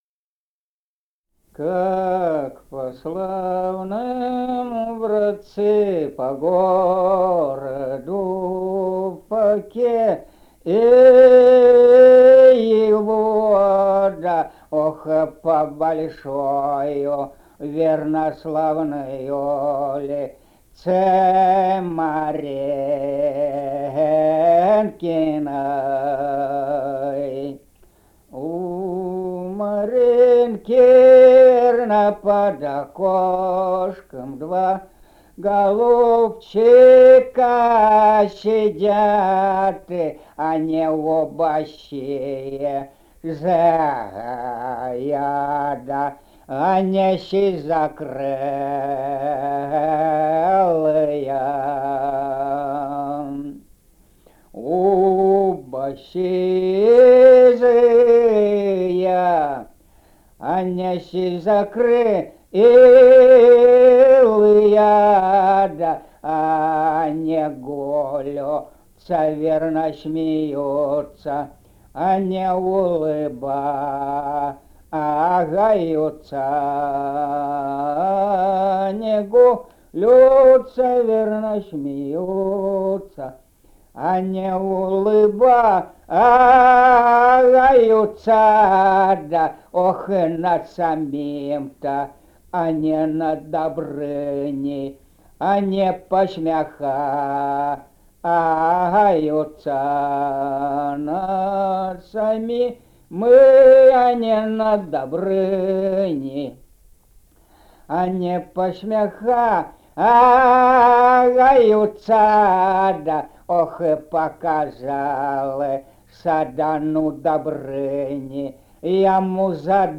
полевые материалы
Казахстан, г. Уральск, 1972 г. И1312-26